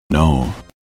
No (deep)